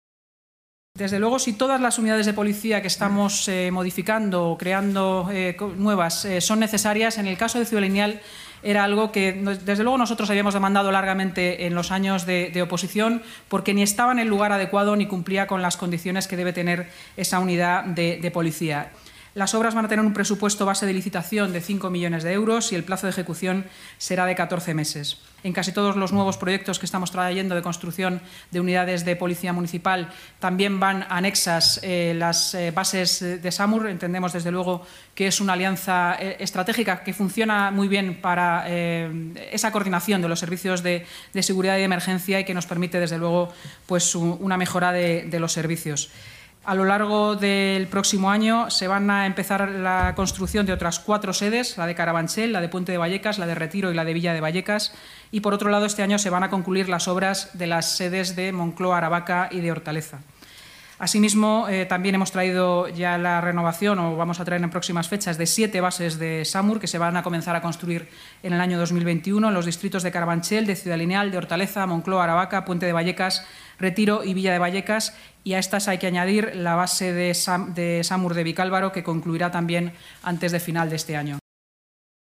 Nueva ventana:Inmaculada Sanz, portavoz municipal